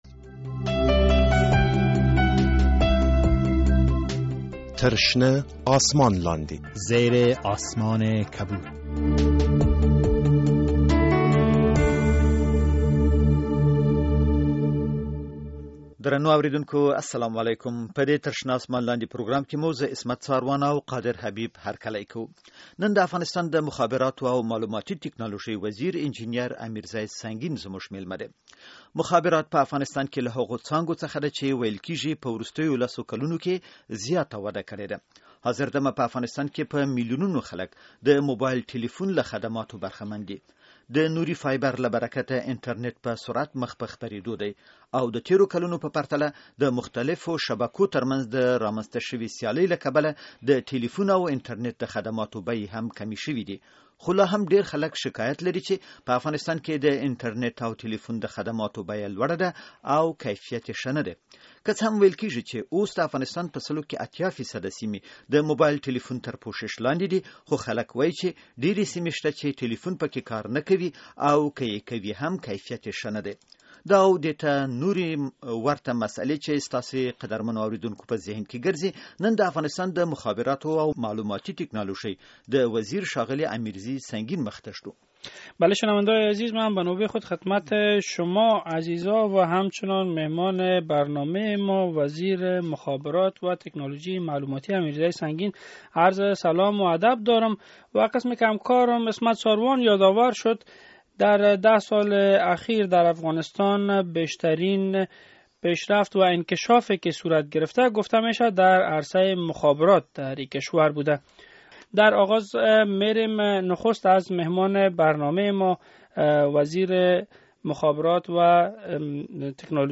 در این شنبه امیرزی سنگین وزیر مخابرات و تکنولوژی معلوماتی مهمان برنامه زیر آسمان کبود بود و به پرسش های شنونده های رادیو آزادی پاسخ گفته است.